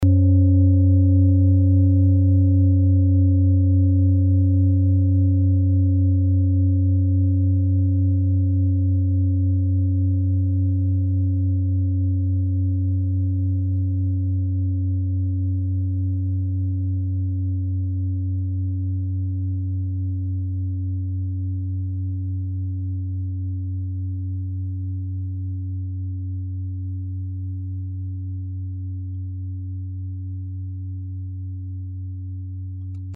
Klangschalen-Typ: Bengalen
Klangschale Nr.1
(Aufgenommen mit dem Filzklöppel/Gummischlegel)
klangschale-set-2-1.mp3